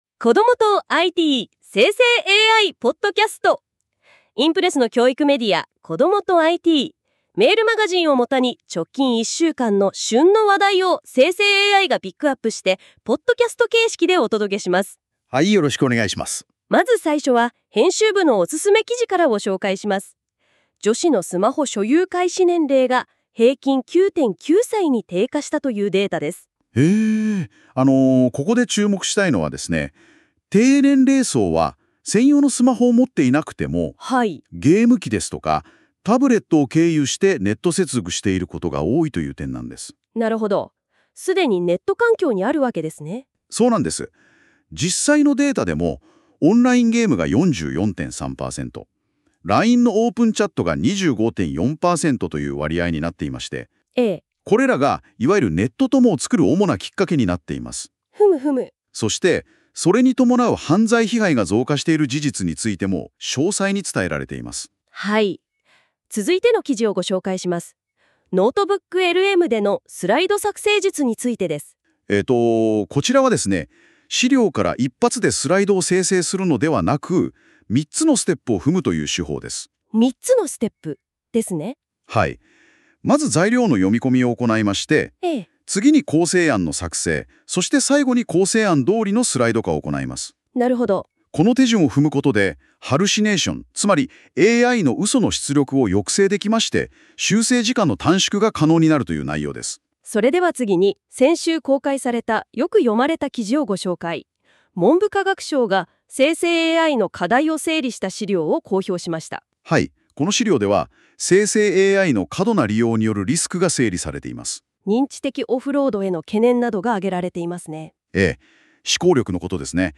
この記事は、『こどもとIT』メールマガジンを元に、先週１週間の旬の話題をNotebookLMでポッドキャストにしてお届けする、期間限定の実験企画です。 ※生成AIによる読み上げは、不自然なイントネーションや読みの誤りが発生します。 ※この音声は生成AIによって記事内容をもとに作成されています。